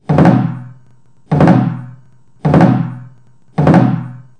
Trống Chiến
Tà rụt: Ví dụ (387-010)